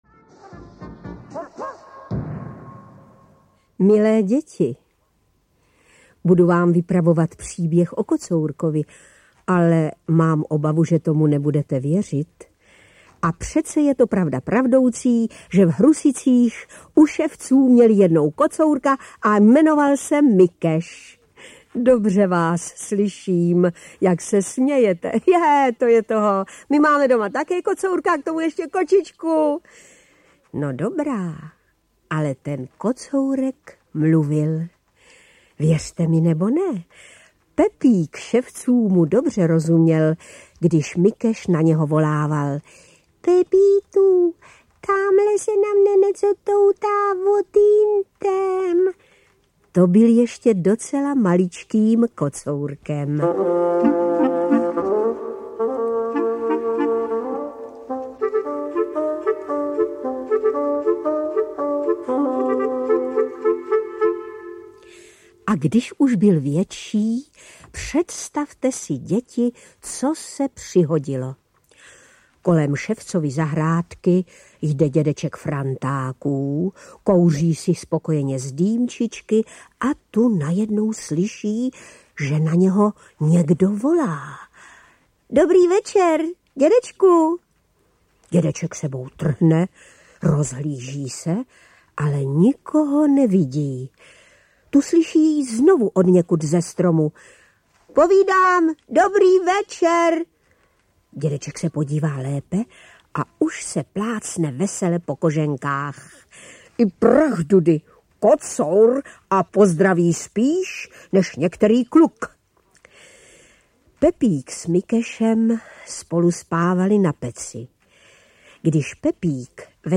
Kocour Mikeš Díl 1. audiokniha
Ukázka z knihy